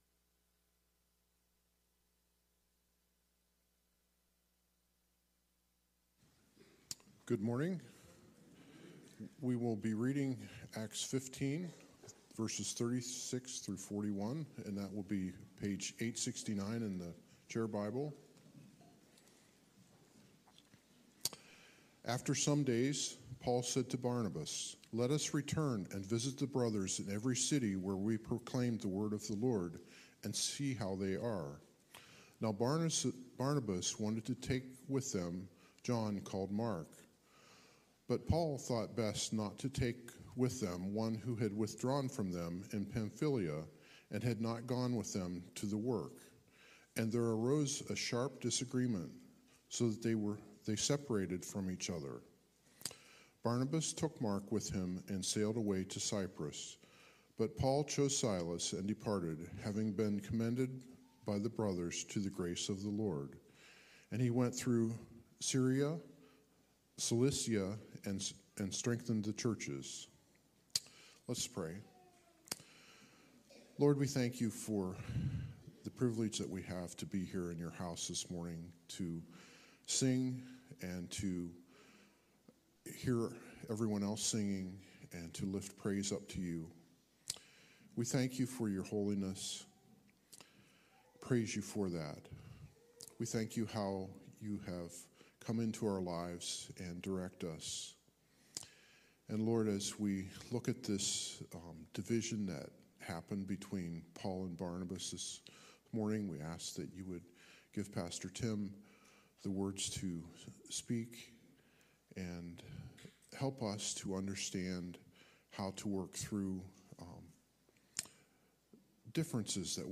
Sermons | Calvary Baptist Church